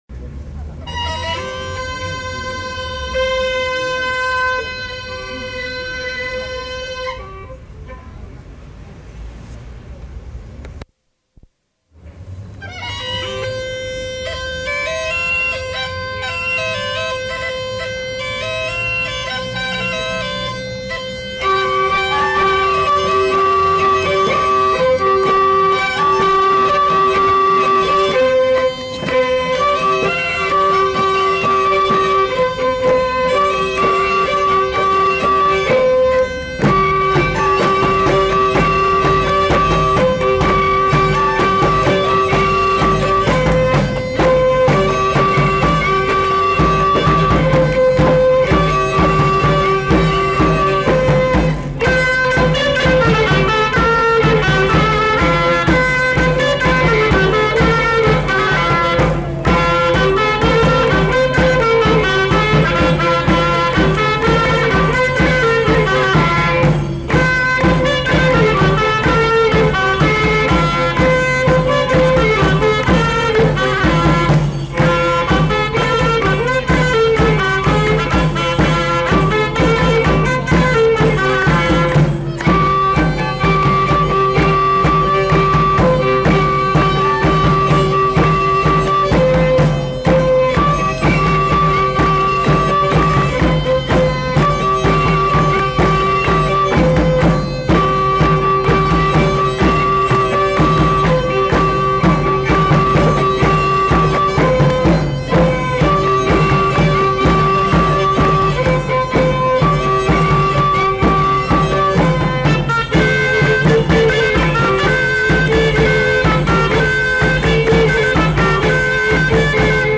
Aire culturelle : Pays d'Oc
Lieu : Pinerolo
Genre : morceau instrumental
Instrument de musique : graile ; violon ; boha ; grosse caisse
Danse : bourrée d'Ariège